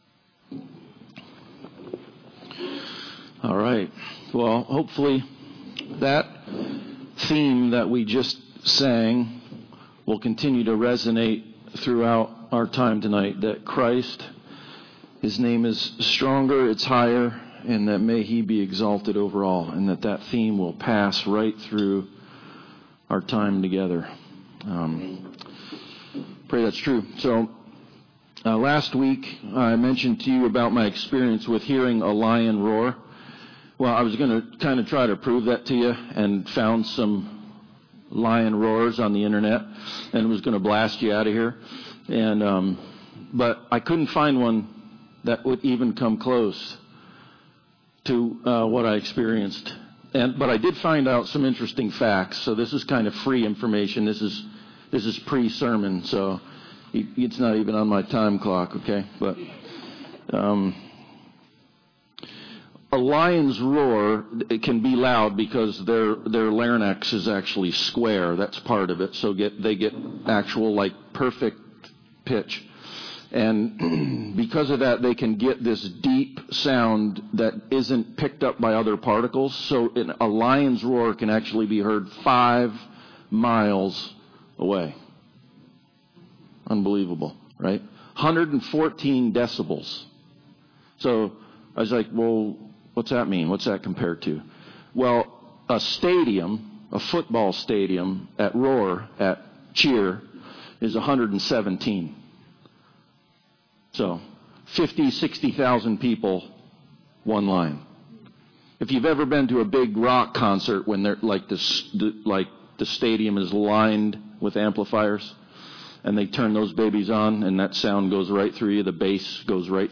Passage: Psalm 63:1-8 Service Type: Sunday Service Bible Text